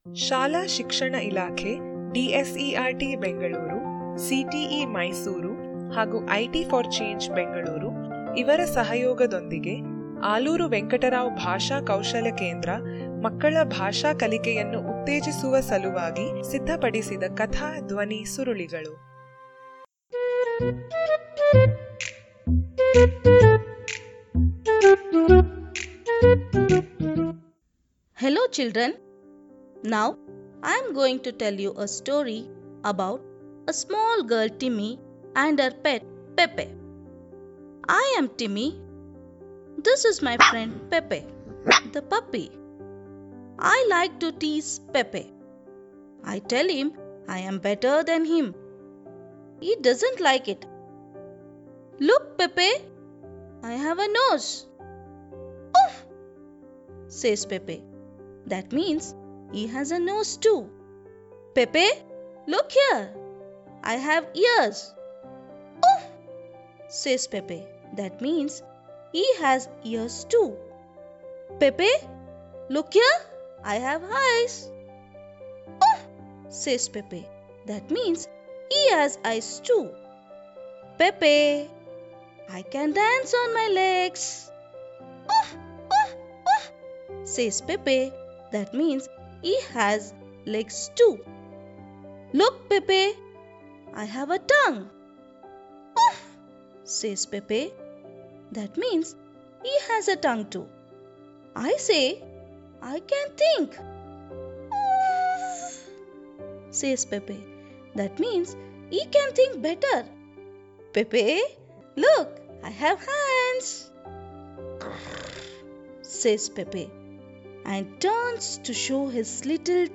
Timmy And Pepe - Audio Story Activity Page